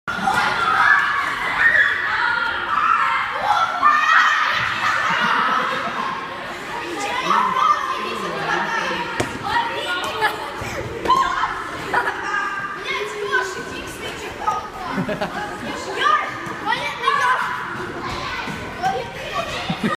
Recess First Graders
• Category: School Break 1301
On this page you can listen to audio recess first graders.